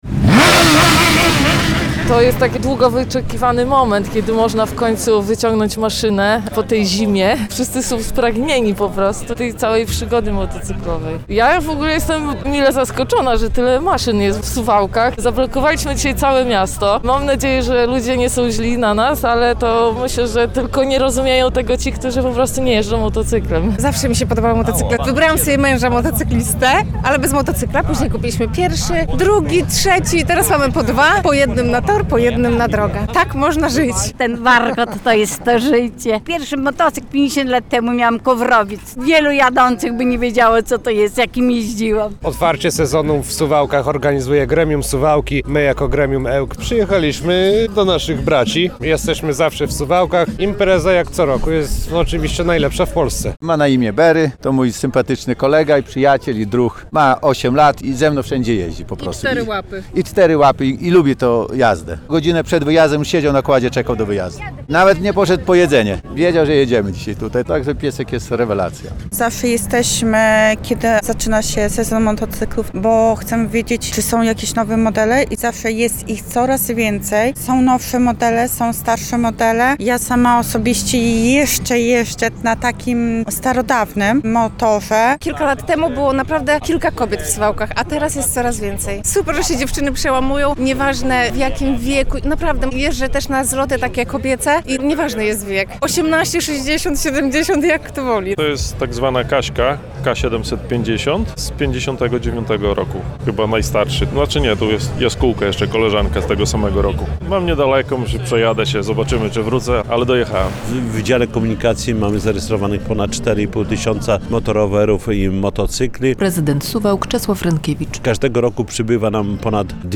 Otwarcie sezonu motocyklowego - relacja
Była parada i wystawa maszyn w Parku Konstytucji 3 Maja, a przy okazji spotkania pasjonatów motoryzacji i radość z wspólnej jazdy.